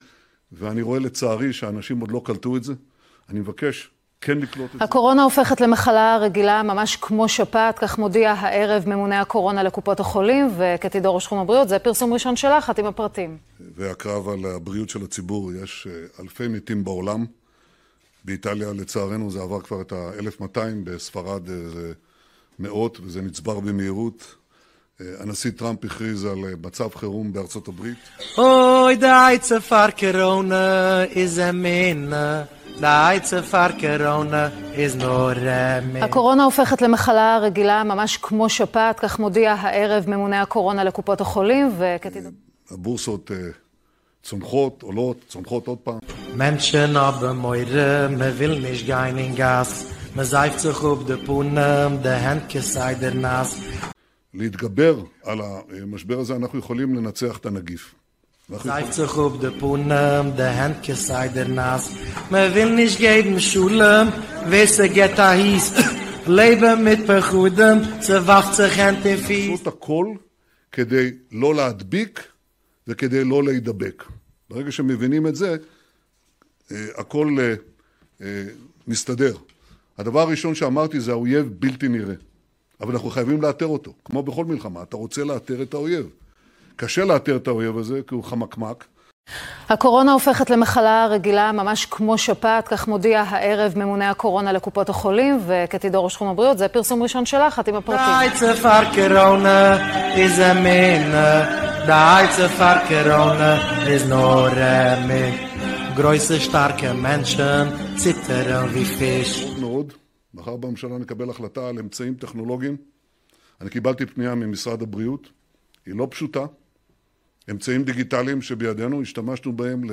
כולנו סבונים קריאה נרגשת